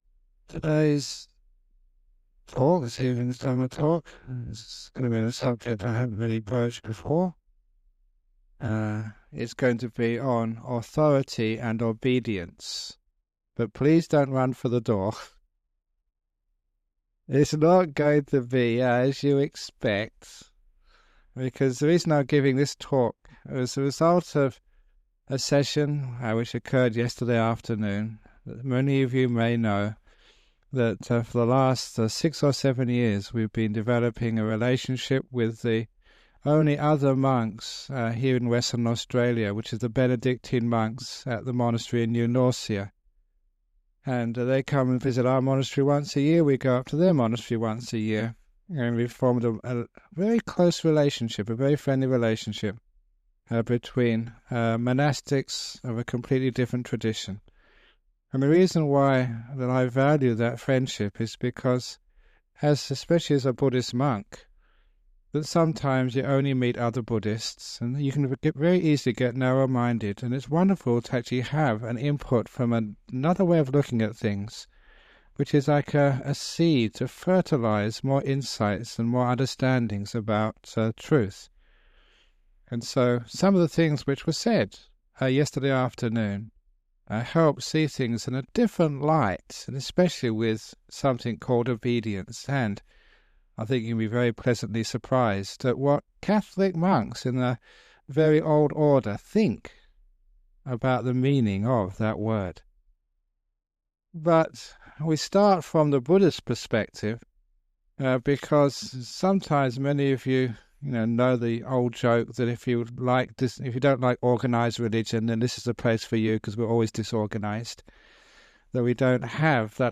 1 Deeper than reason - Beyond belief 1:03:09 Play Pause 1M ago 1:03:09 Play Pause Play later Play later Lists Like Liked 1:03:09 Ajahn Brahm gives a talk about faith and reason, and the path of truth that lays beyond both. — This dhamma talk was originally recorded using a low quality MP3 to save on file size on 27th October 2006. It has now been remastered and published by the Everyday Dhamma Network, and will be of interest to his many fans.